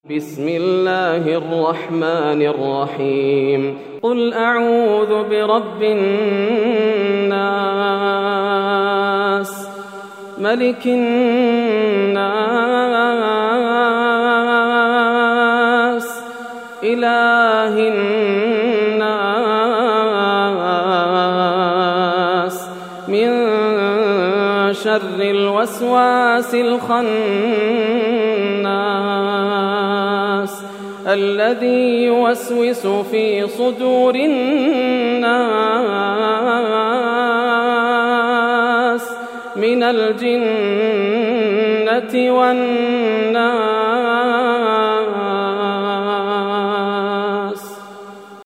سورة الناس > السور المكتملة > رمضان 1431هـ > التراويح - تلاوات ياسر الدوسري